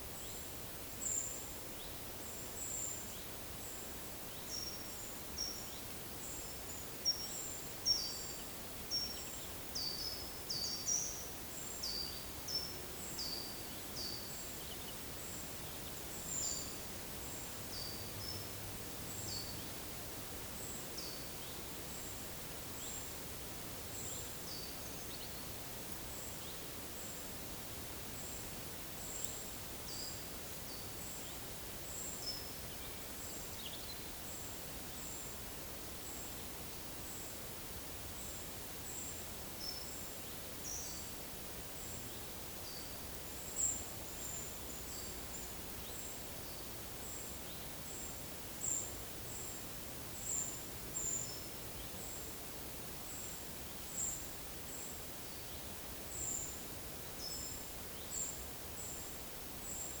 Monitor PAM
Certhia familiaris
Certhia brachydactyla
Turdus iliacus
Regulus ignicapilla
Erithacus rubecula